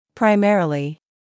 [praɪ’merəlɪ][прай’мэрэли]в основном, главным образом